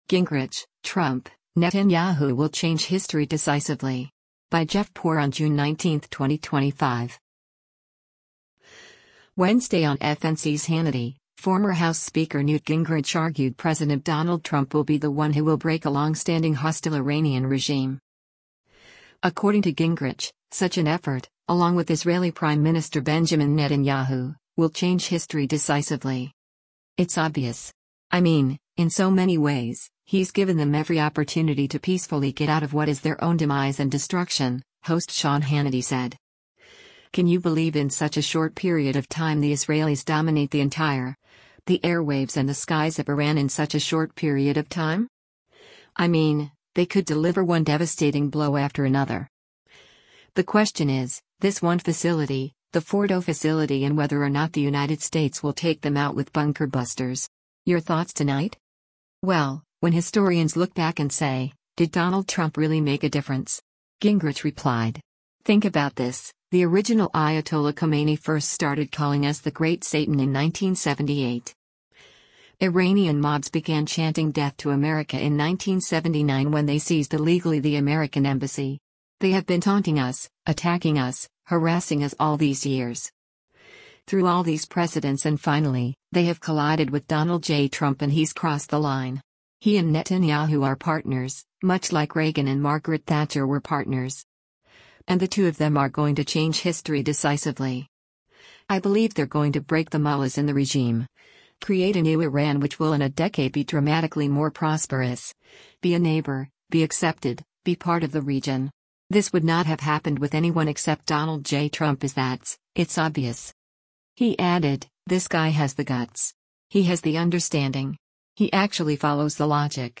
Wednesday on FNC’s “Hannity,” former House Speaker Newt Gingrich argued President Donald Trump will be the one who will break a long-standing hostile Iranian regime.